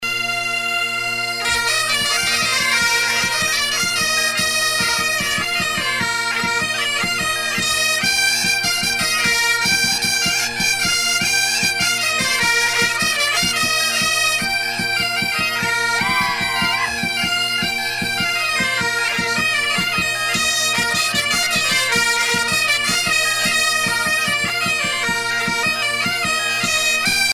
Usage d'après l'analyste gestuel : danse
Catégorie Pièce musicale éditée